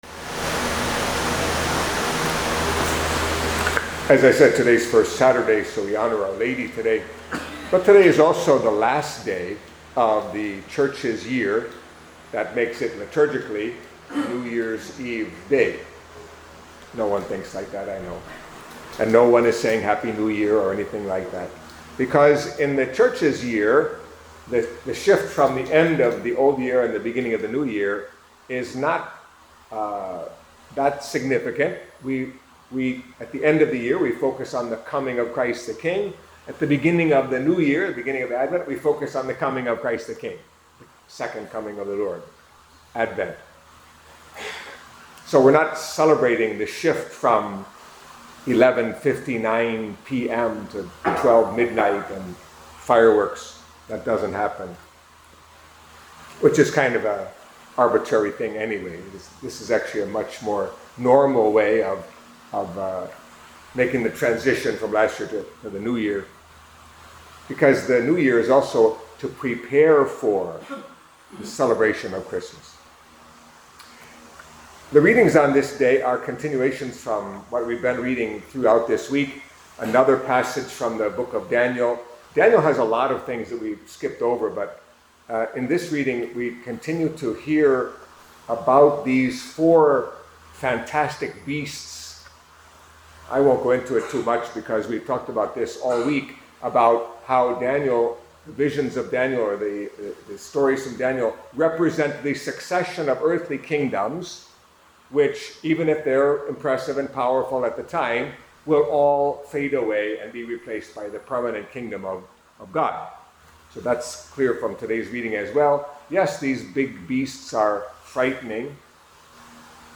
Catholic Mass homily for Saturday of the Thirty-Fourth Week in Ordinary Time